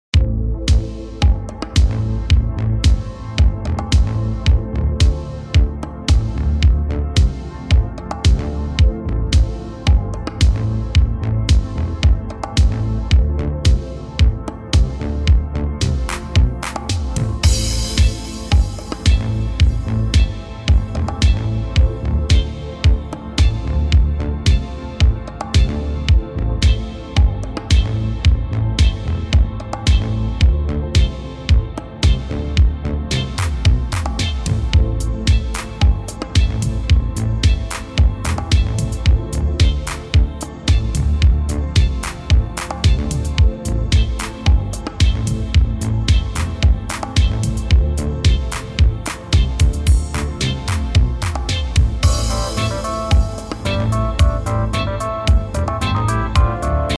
remixed with a fresh, modern groove.